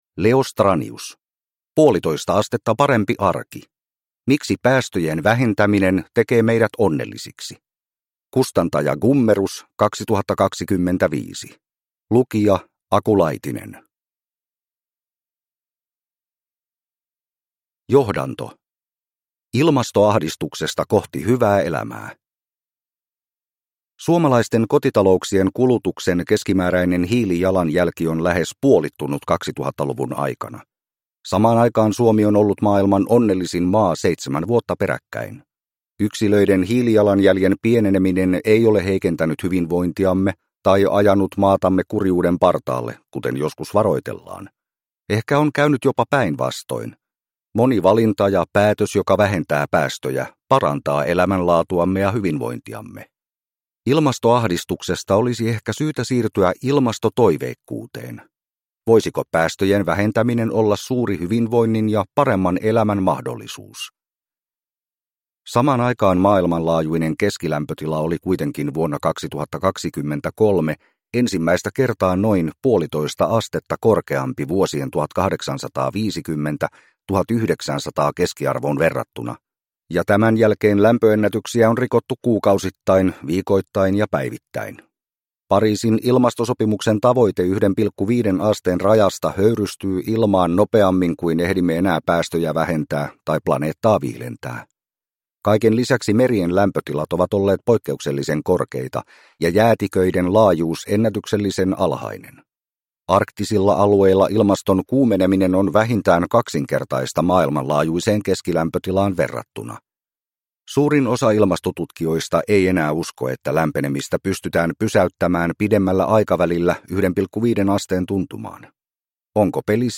1,5 astetta parempi arki – Ljudbok